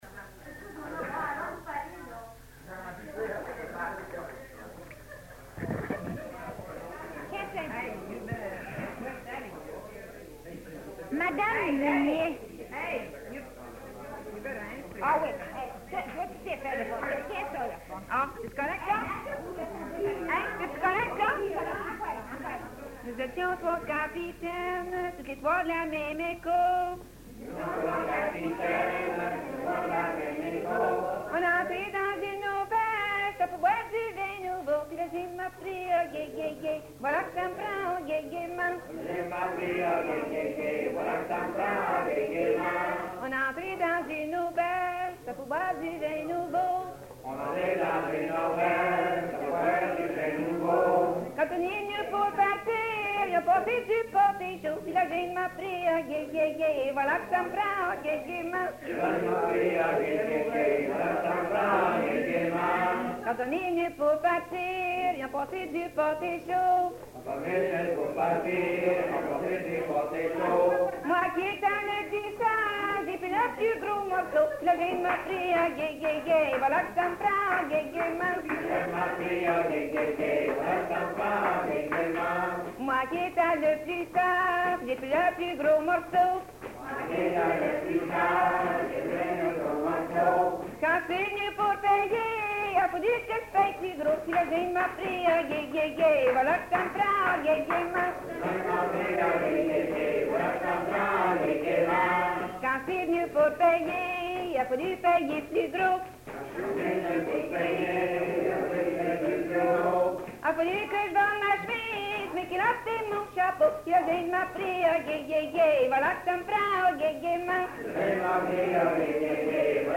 Folk Songs, French--New England